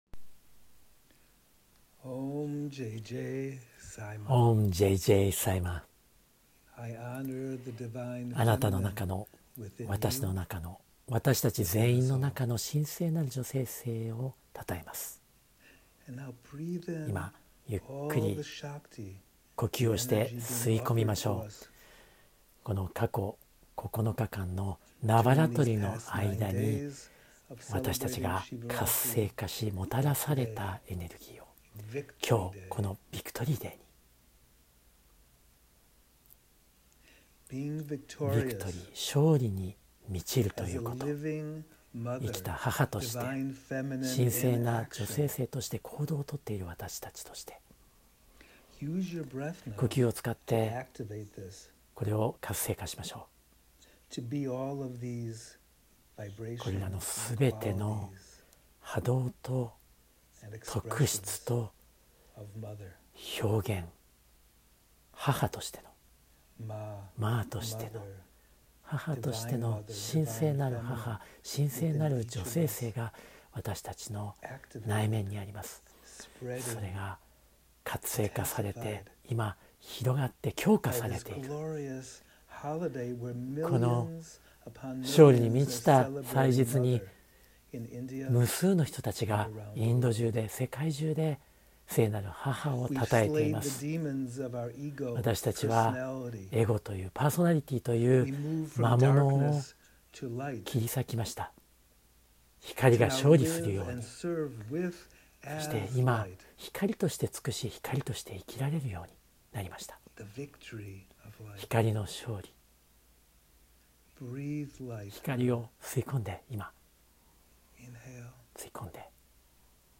ナヴァラトリ-ビクトリー・デイ-瞑想.mp3